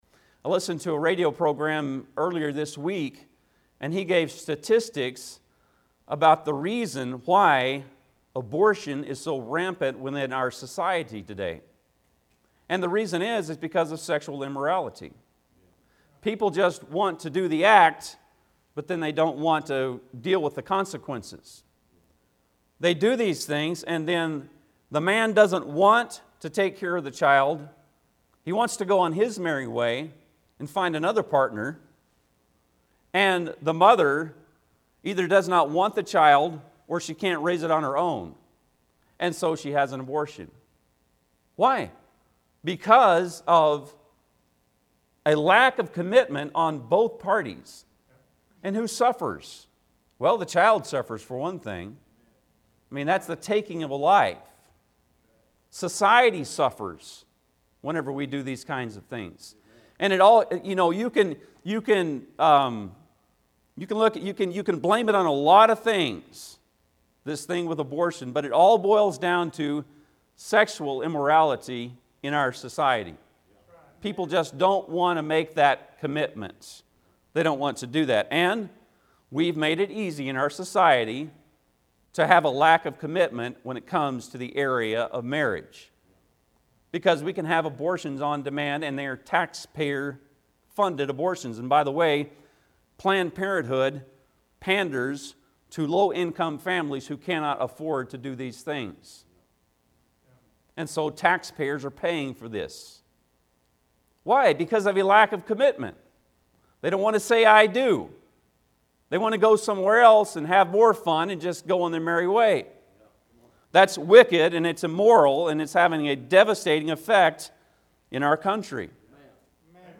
Passage: Psalms 101:1-8 Service Type: Sunday pm